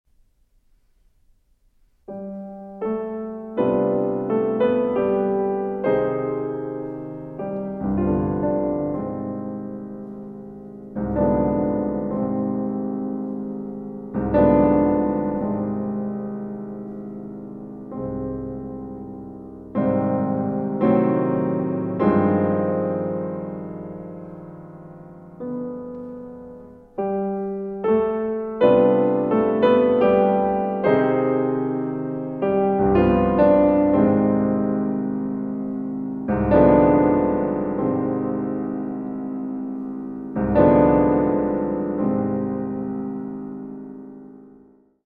Andante patetico e sempre pesante e poco rubato (2:35)